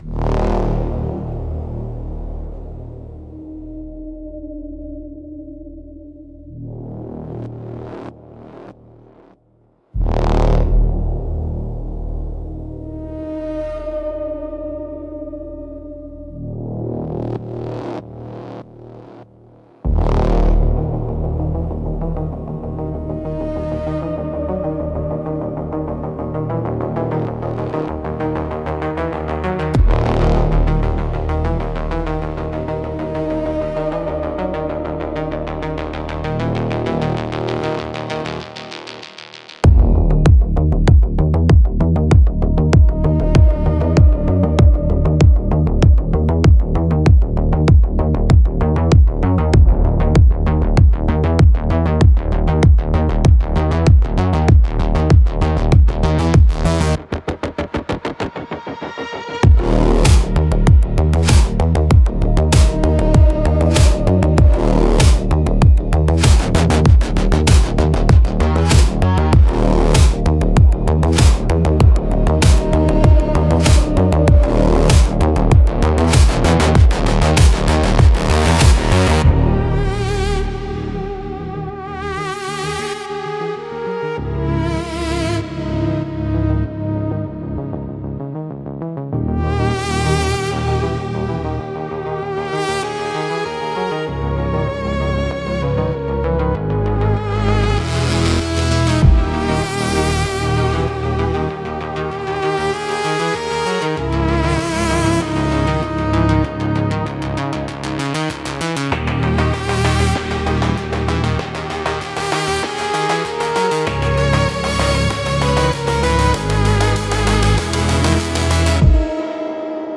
Instrumental - A Flicker in the Geodesic Dome 4.04